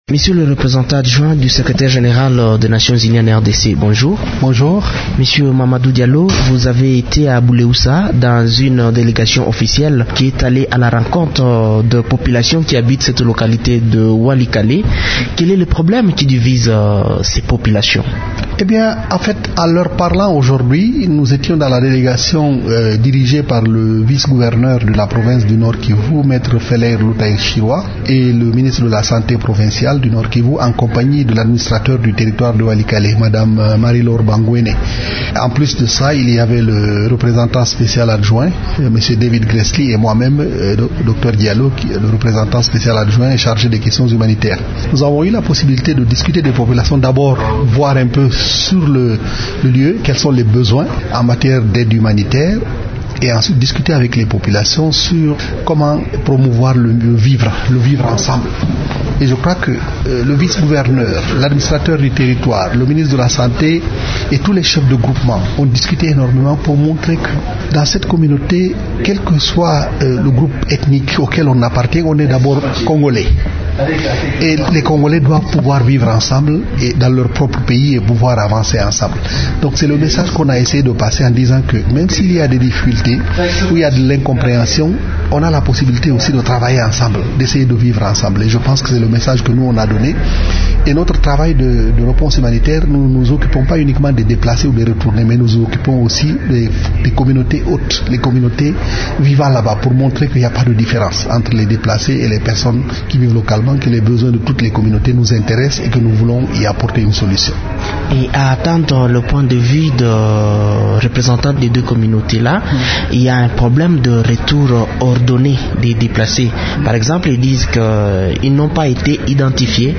Il rend compte dans cette interview de l’essentiel du message livré par la délégation mixte Monusco - Gouvernement provincial du Nord-Kivu aux habitants du groupement Ikobo en territoire de Walikale.